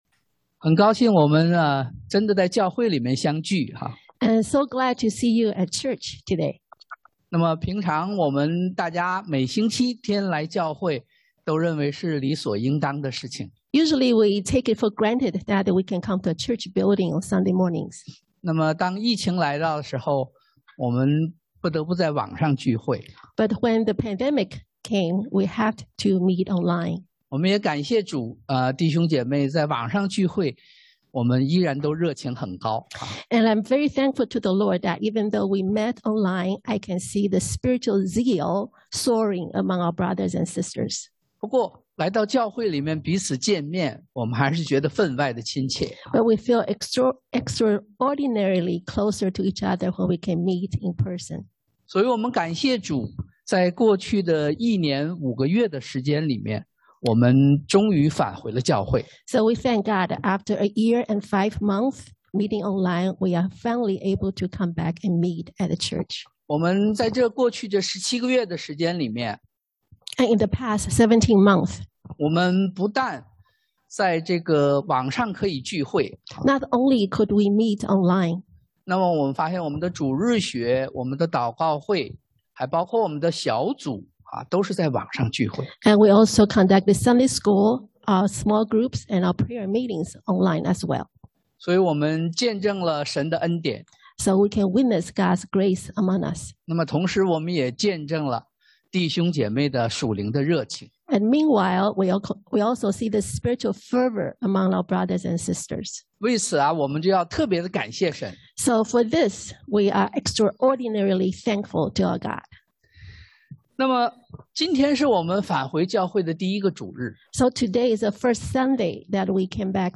傳 Ecc 3:1-14 Service Type: Sunday AM Reflect On The Pandemic 反思疫情 傳Ecc 3:1-14 1.